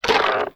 ALIEN_Communication_19_mono.wav